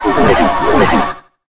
Cri de Nigosier dans sa forme Gobe-Tout dans Pokémon HOME.
Cri_0845_Gobe-Tout_HOME.ogg